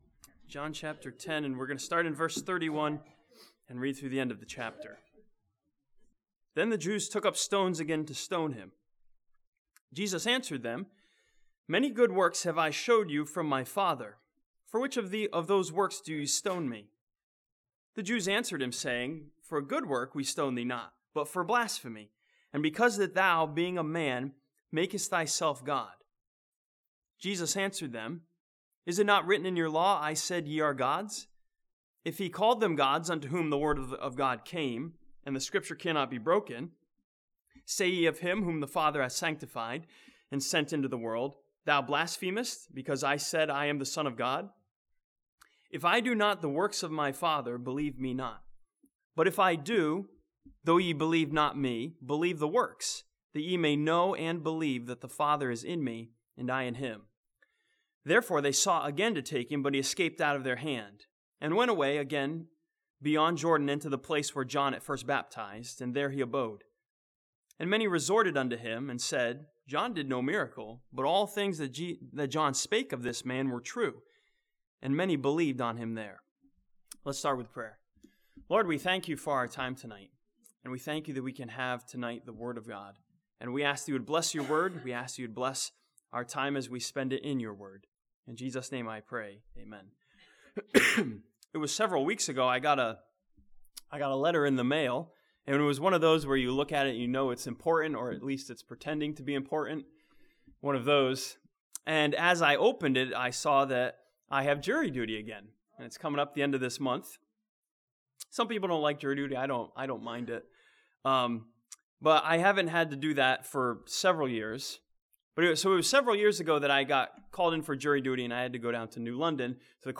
This sermon from John chapter 10 examines the peoples claim that Jesus was guilty and asks the question if we too are guilty of godliness.